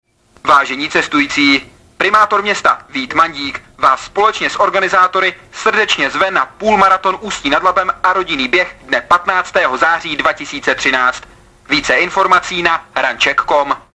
Tak, jako v minulém roce, tak i letos je kromě vizuálních informací cestujícím ve vybraných zastávkách pouštěno i hlášení.
- Hlášení o půlmaratonu v Ústí nad Labem si